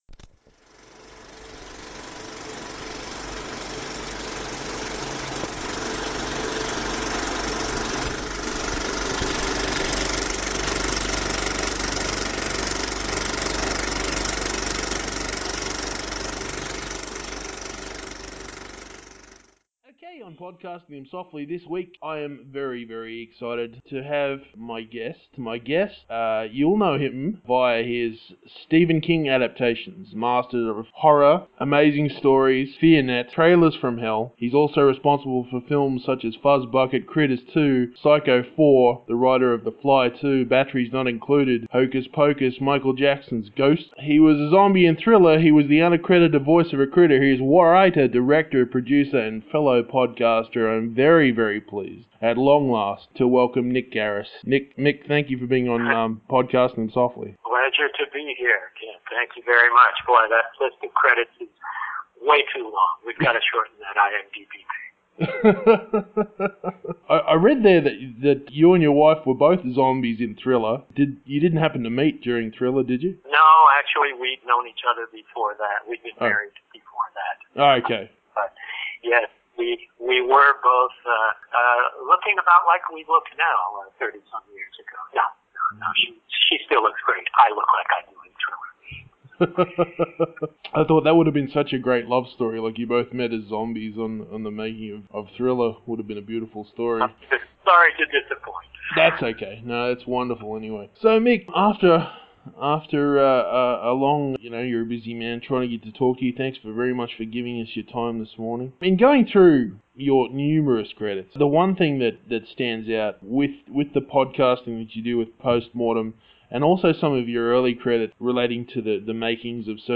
“CHEESEBURGERS, NO BONES!” : An Interview with Mick Garris